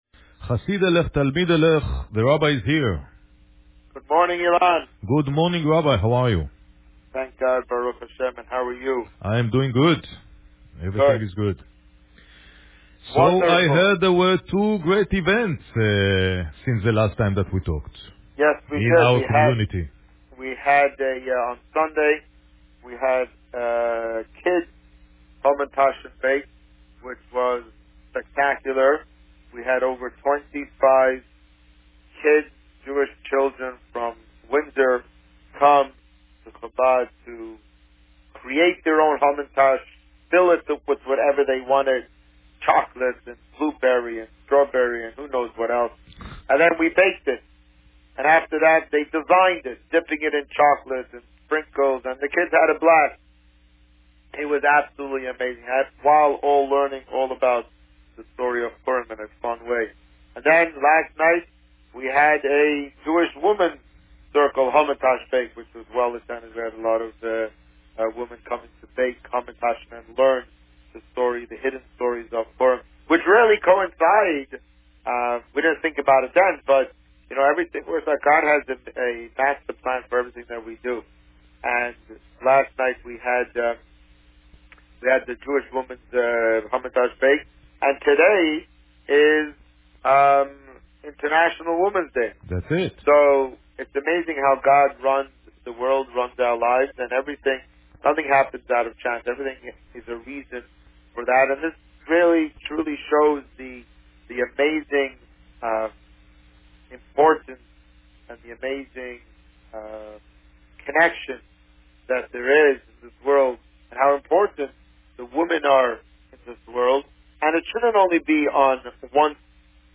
On March 7, 2019, the Rabbi spoke about Parsha Pekudei and the upcoming Purim festivities. Listen to the interview here.